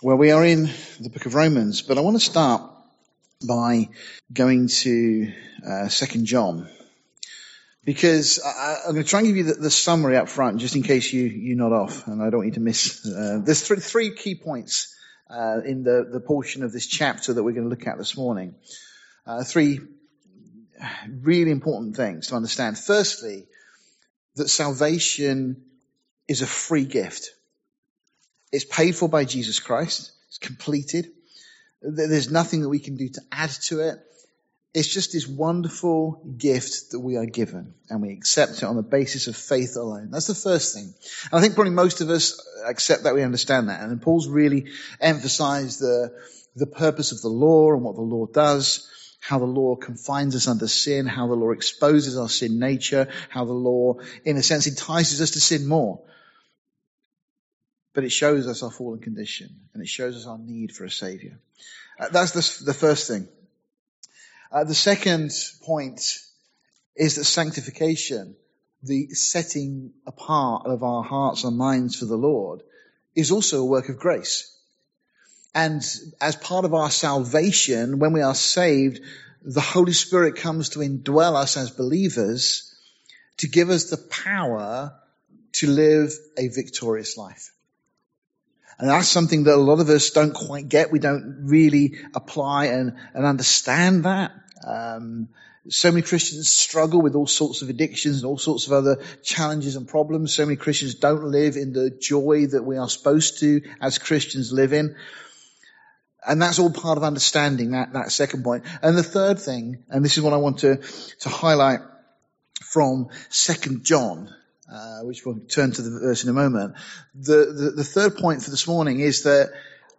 Series: Sunday morning studies Tagged with verse by verse